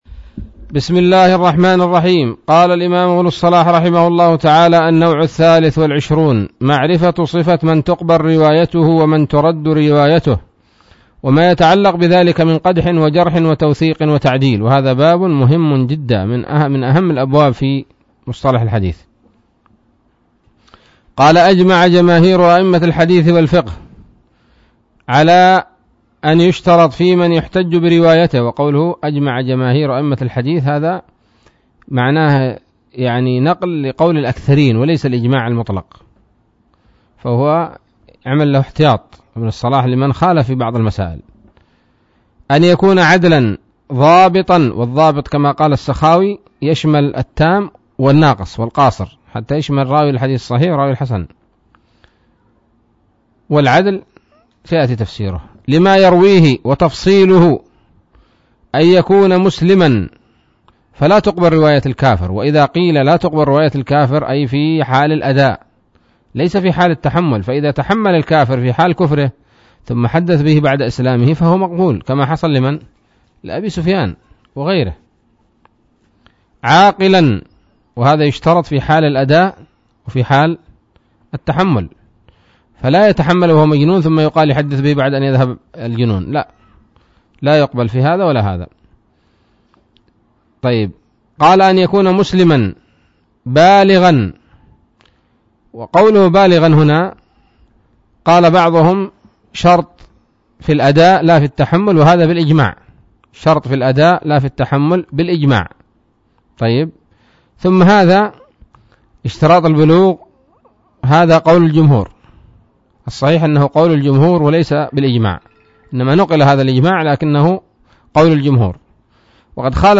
الدرس السابع والأربعون من مقدمة ابن الصلاح رحمه الله تعالى